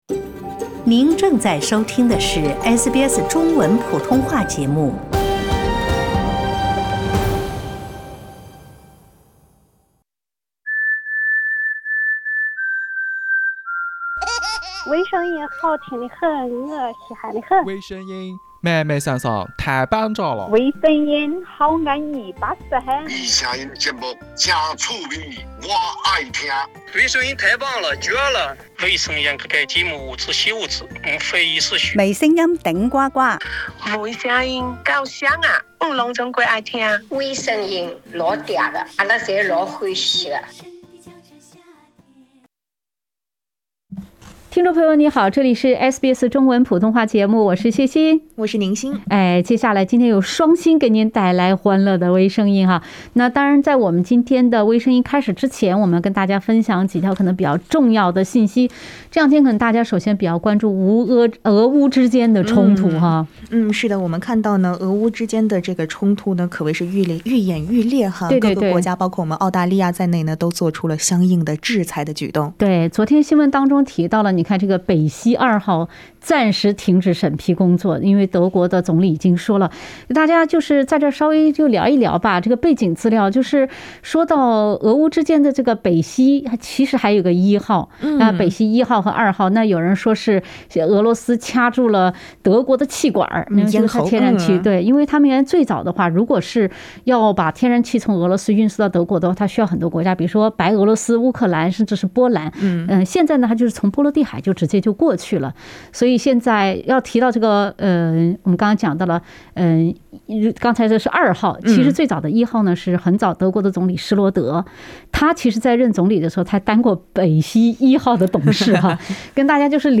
（点击封面图片，收听风趣对话）